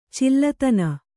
♪ cillatana